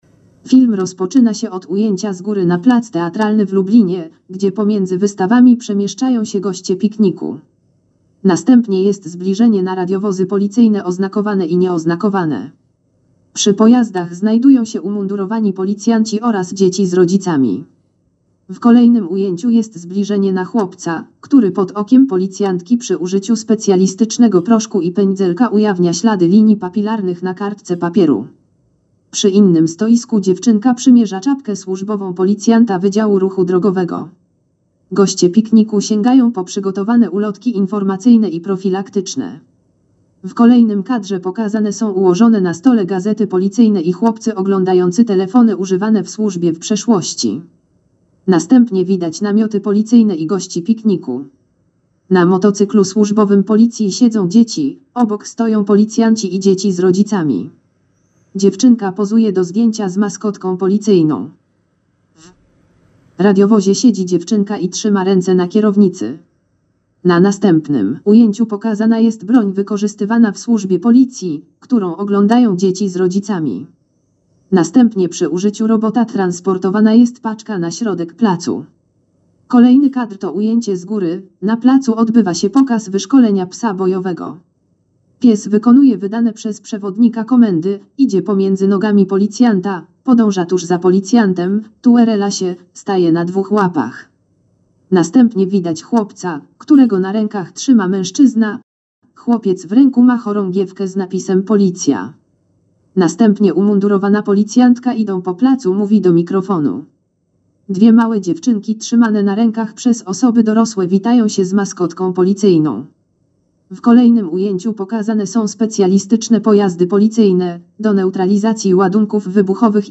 Nagranie audio Audiodeskrypcja filmu Wojewódzkie Obchody Święta Policji w Lubelskim Garnizonie